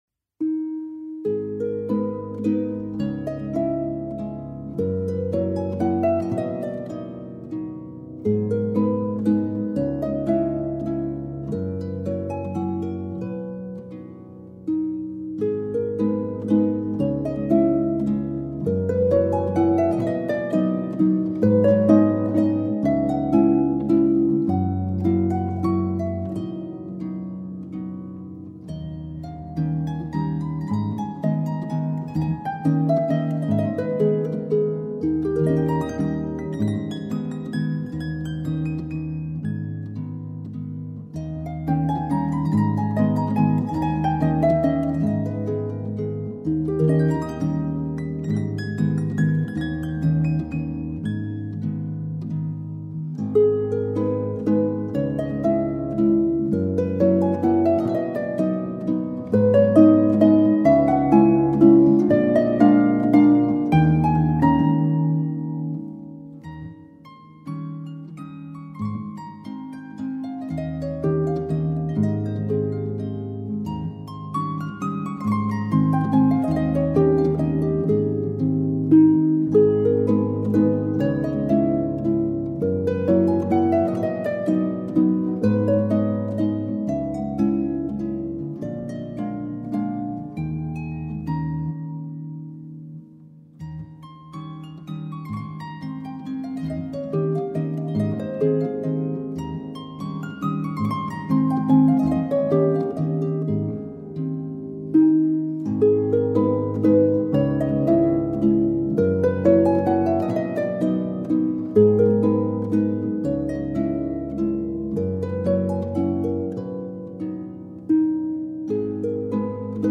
اثر زیبا و رویاییِ فردریک شوپن، موسیقی‌دان برجسته‌ی لهستانی